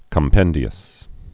(kəm-pĕndē-əs)